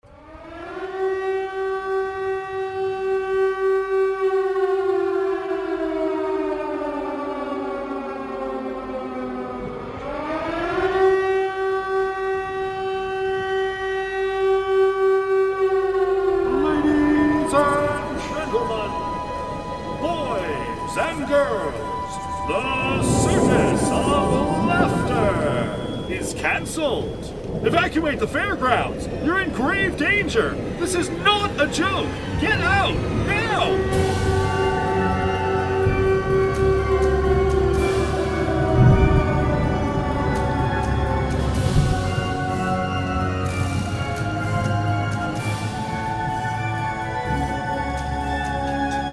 Each world intricately recreates familiar scenes laid out exactly as they once were, brought back to life through theatrical lighting transitions and their own iconic background music tracks, each leading with a thematic mini-preshow introductory announcement.
Colorful swirling lights and warped calliope music signal Truth Seekers’ return to where this all began a decade ago: The Circus of SLaughter.